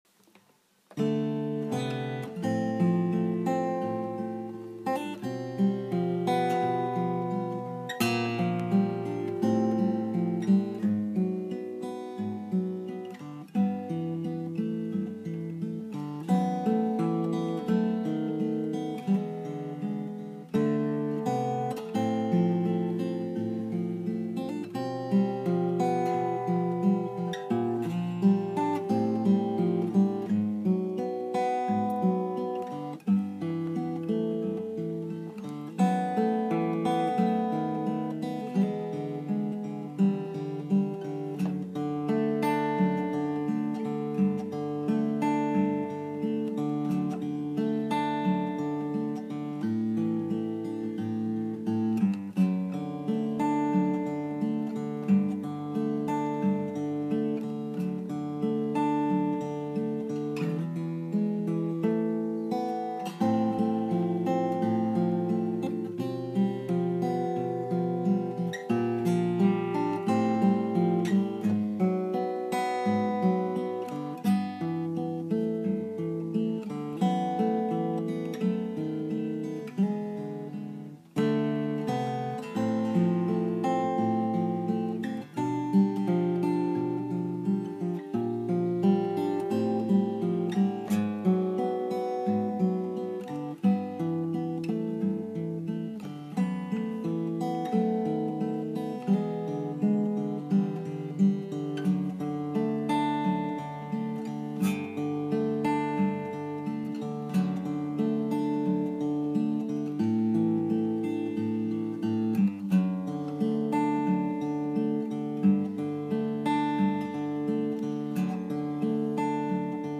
Клэптон играет переборчики и выкладывает у себя в мордокниге .
И переборчики такие вдумчивые ,кайфовые .